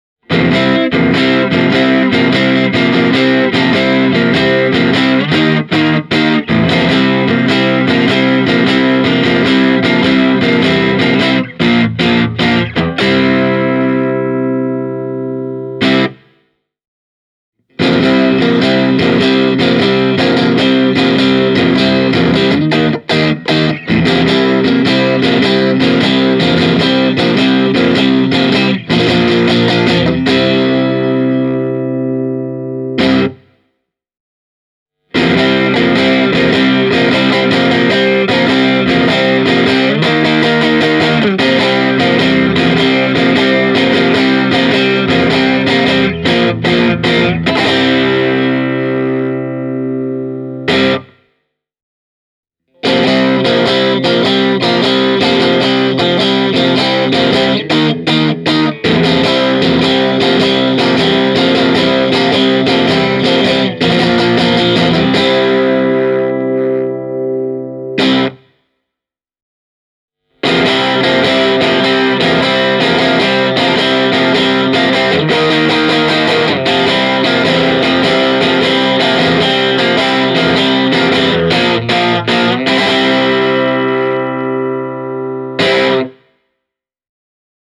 Tässä muutama esimerkki GA-112:n soundista ilman ulkoisia efektilaiteita:
Stratocaster – säröllä
stratocaster-e28093-crunchy.mp3